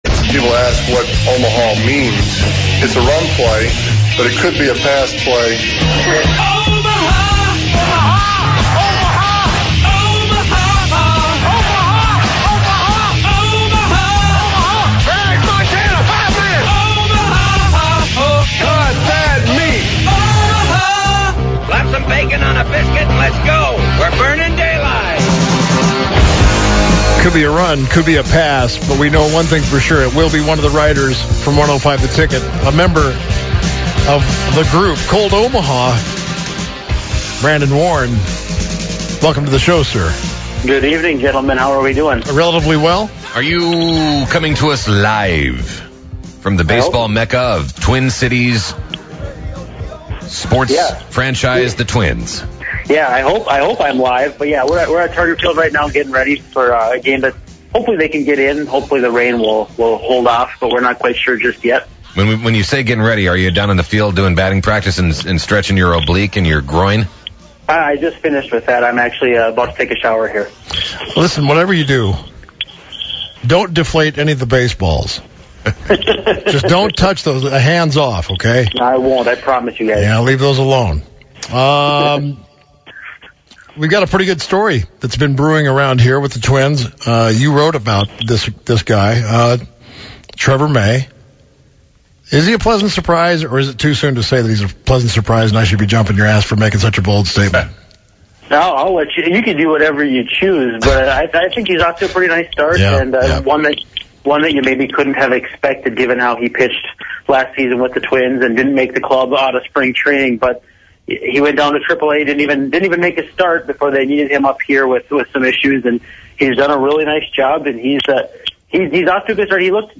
Then they take calls and talk about Deflategate.